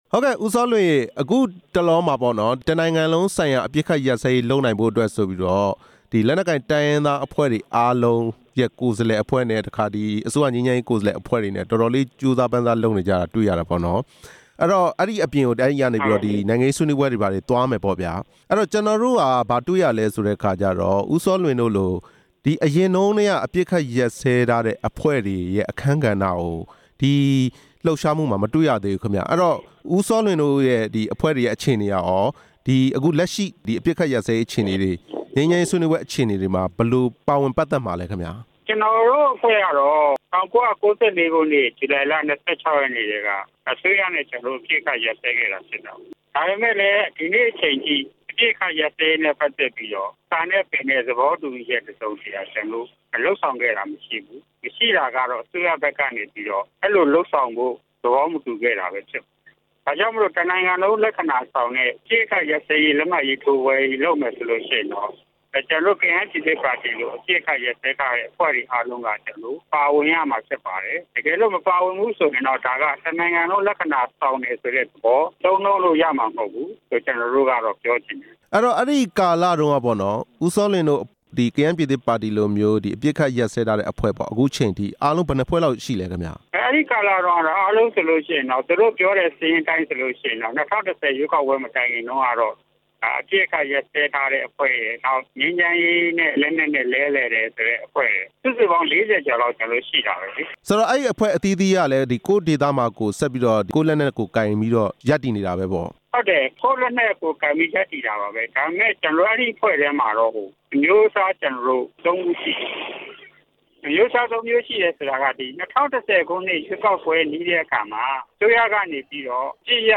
တစ်နိုင်ငံလုံးဆိုင်ရာ အပစ်ရပ်စဲရေး ဆွေးနွေးပွဲအကြောင်း ကယန်းပြည်သစ်ပါတီနဲ့ မေးမြန်းချက်